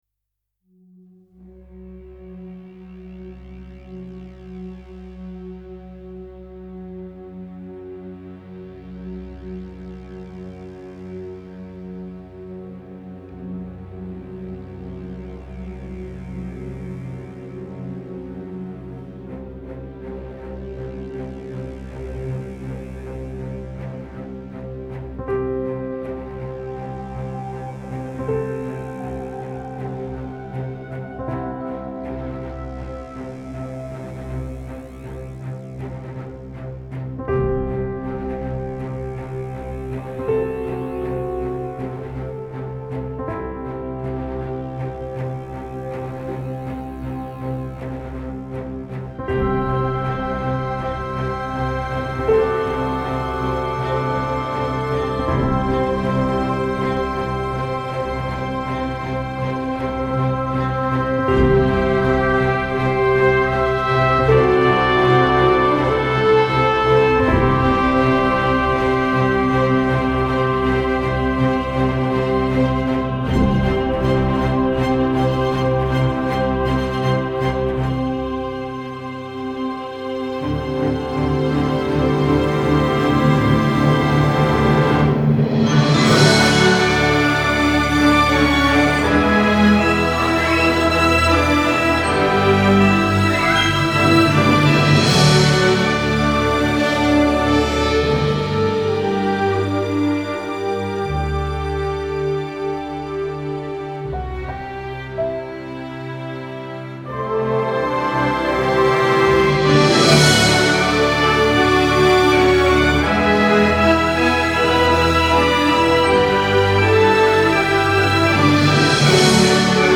Score composer for film and media.